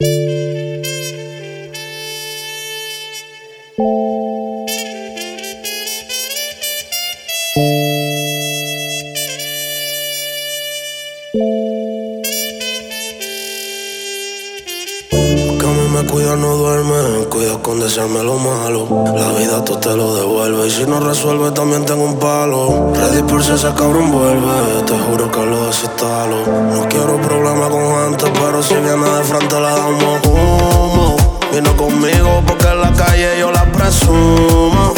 Pop Latino, Latin